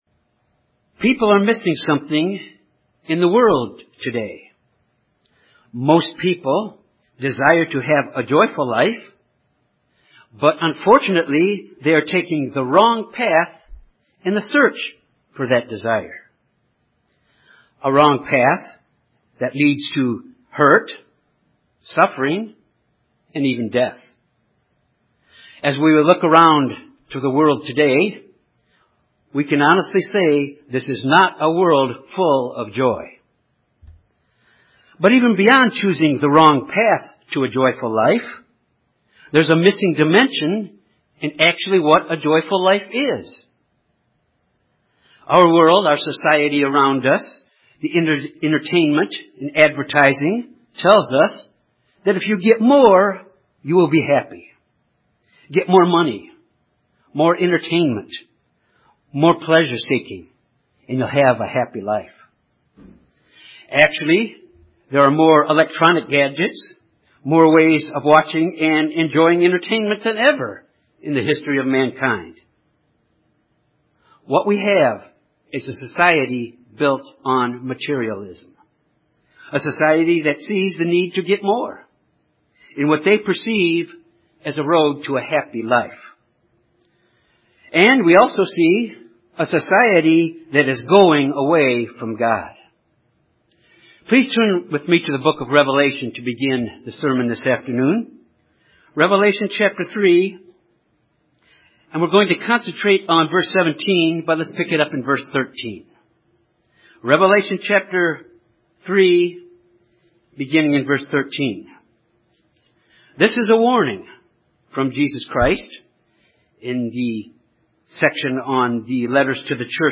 This sermon examines four ways to develop and express the fruit of joy in our lives.